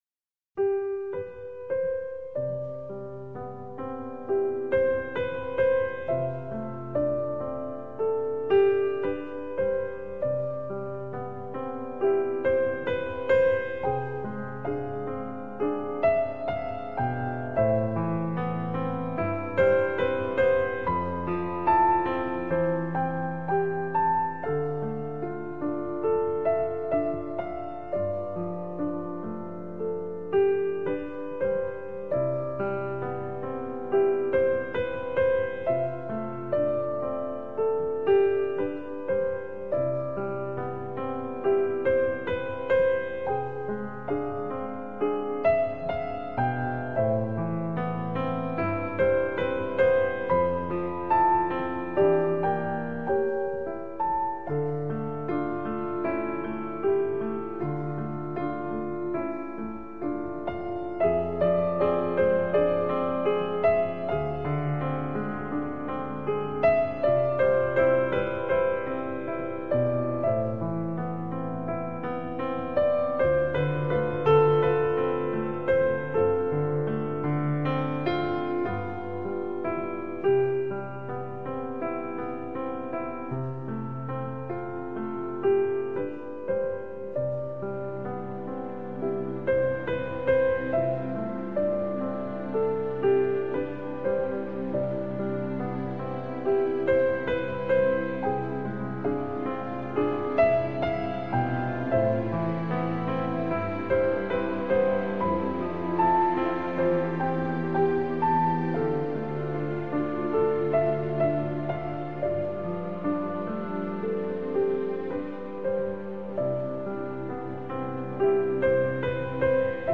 은 캐나다 퀘벡Quebec 출신의 뉴에이지 피아니스트입니다.
듣기에 어렵지 않고, 언제나 평화롭고 온화해요.